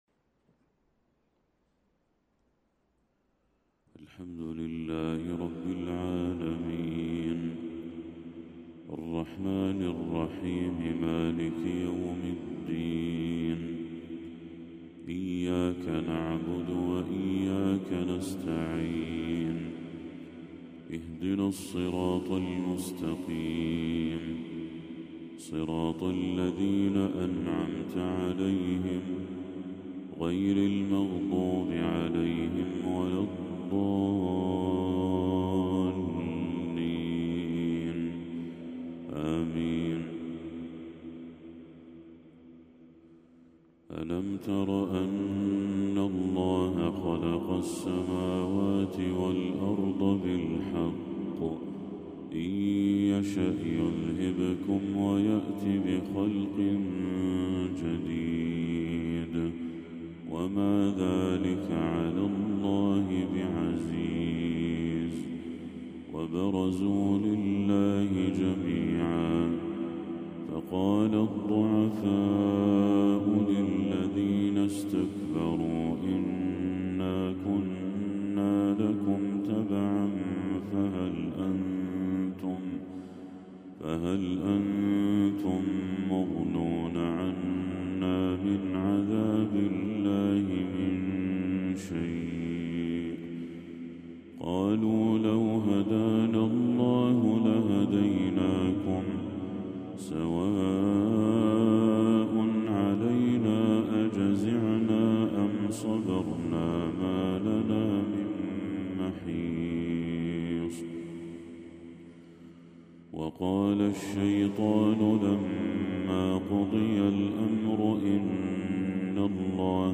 تلاوة بديعة من سورة إبراهيم للشيخ بدر التركي | فجر 19 ربيع الأول 1446هـ > 1446هـ > تلاوات الشيخ بدر التركي > المزيد - تلاوات الحرمين